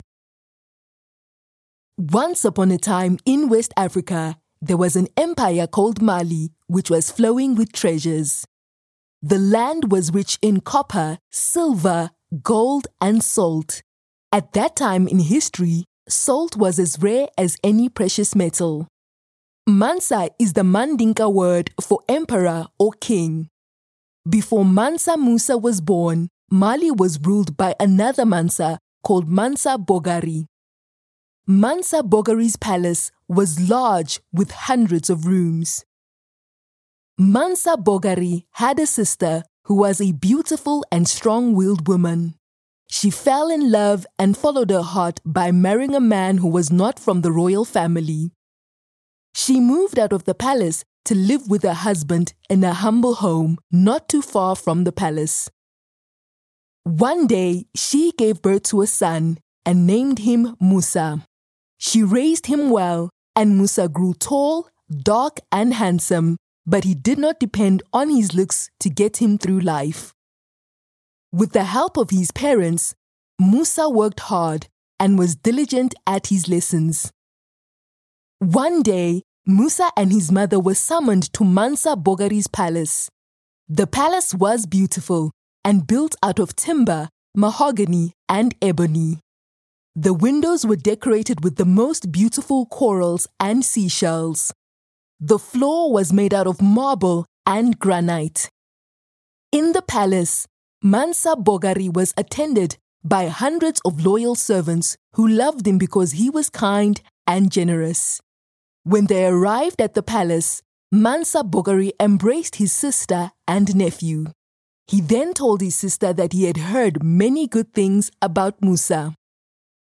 This is the story of Mansa Musa, written in easy to read texts which can be read to young children.